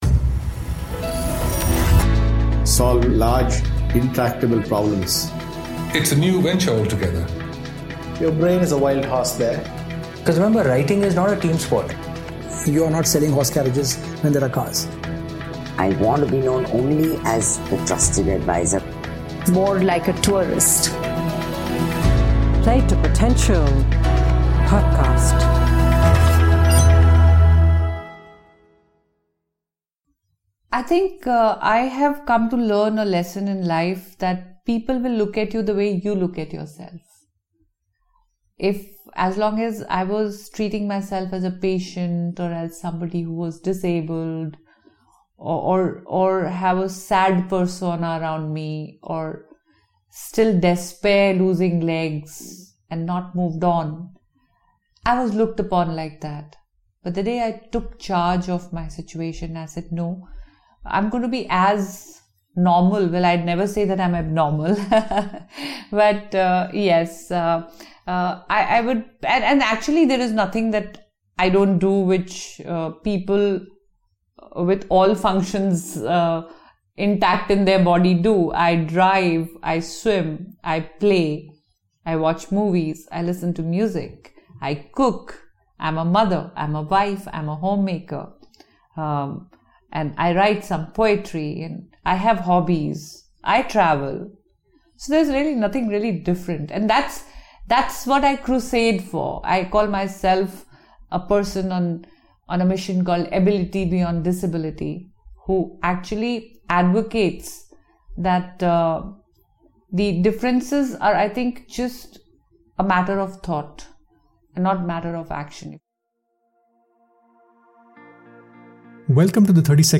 In our conversation, we spoke about her formative years in the Armed Forces and how that moulded her attitudes and approach to life.